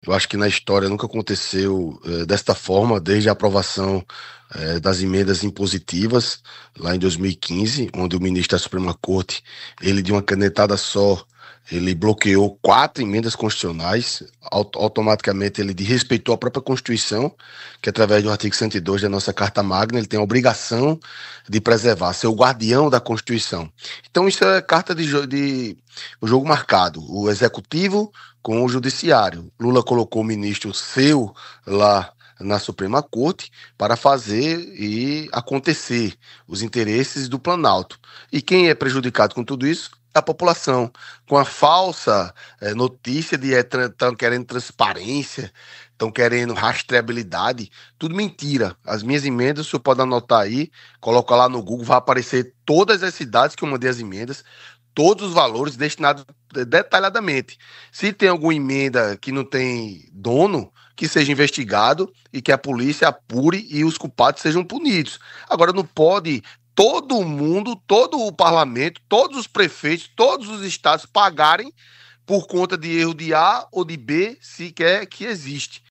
O comentário do parlamentar foi registrado pelo programa Correio Debate, da 98 FM, de João Pessoa, nesta terça-feira (07/01).